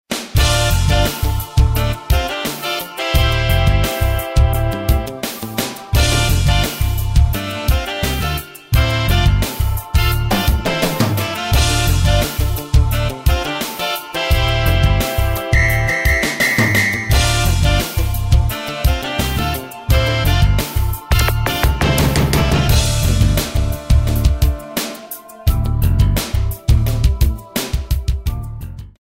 Key of D minor
Backing track only.
Real jazz hits. Real endings. Performance quality audio.